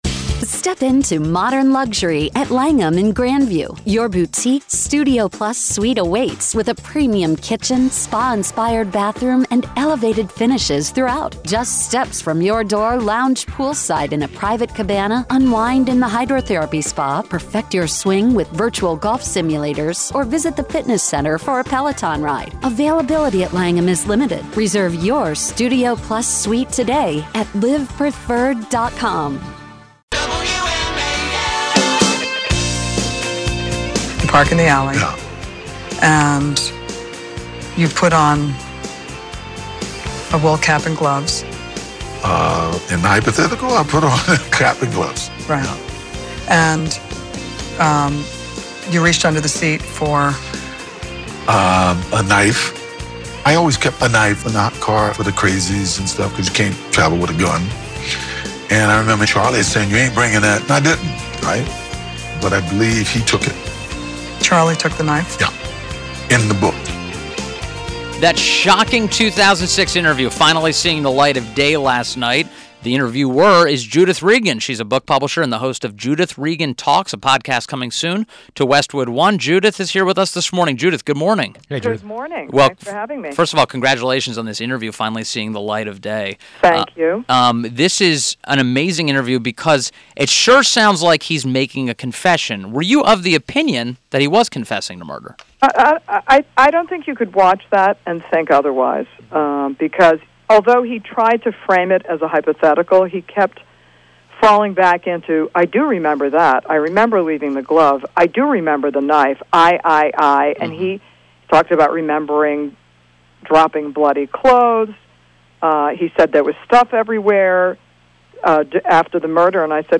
AS HEARD ON WMAL: INTERVIEW – JUDITH REGAN – Book publisher and host of “Judith Regan Talks” podcast coming soon to Westwood One – discussed her bombshell 2006 Fox News interview with OJ Simpson